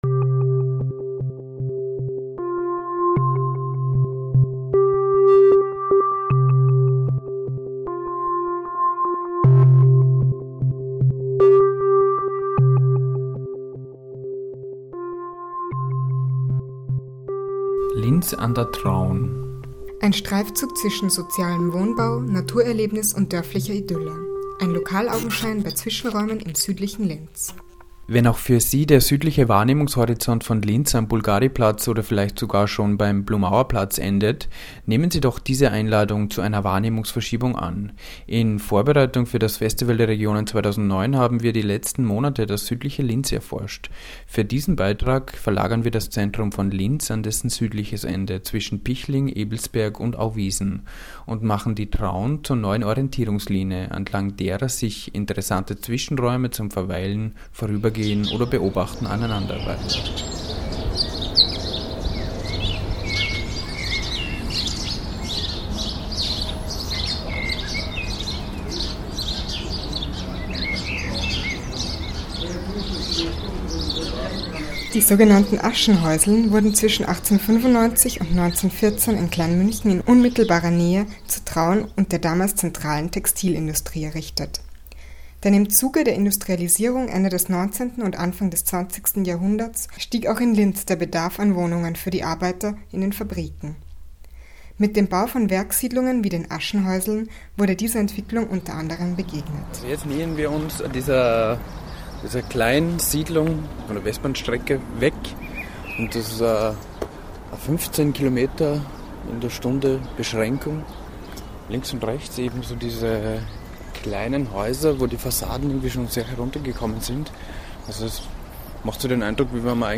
Feature
Format: Stereo 44kHz